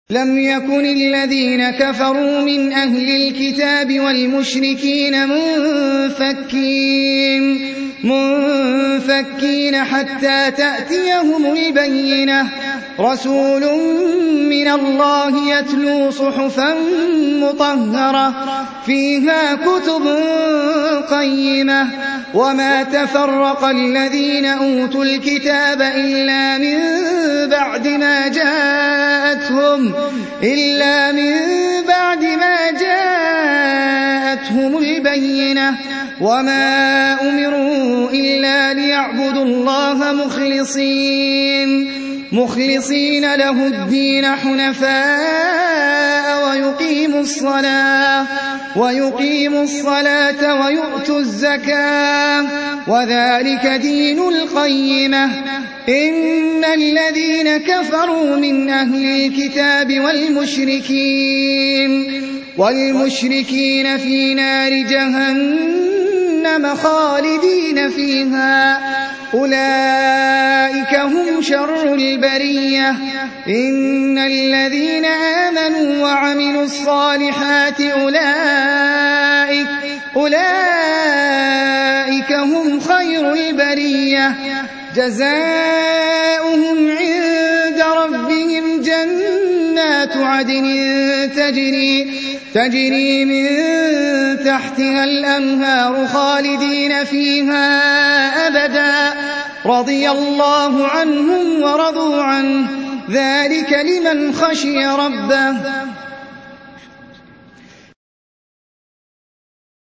98. Surah Al-Baiyinah سورة البينة Audio Quran Tarteel Recitation
Surah Sequence تتابع السورة Download Surah حمّل السورة Reciting Murattalah Audio for 98.